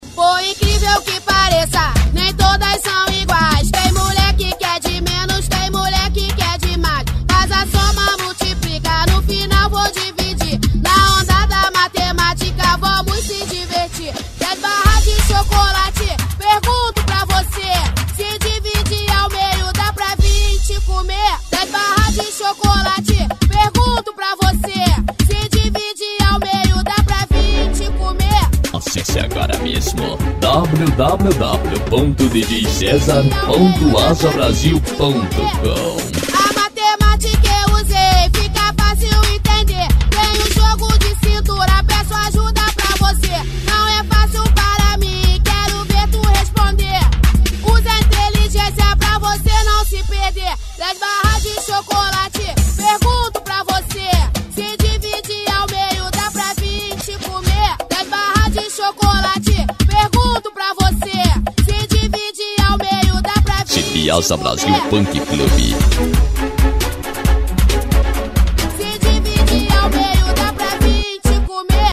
Funk
Funk Nejo